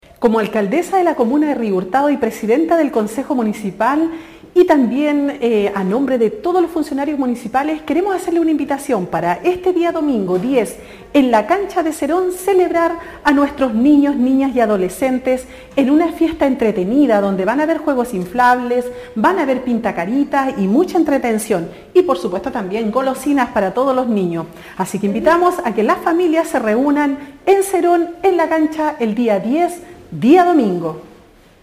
La alcaldesa de Río Hurtado, Carmen Juana Olivares, invita a toda la familia de la comuna a asistir a esta entretenida actividad.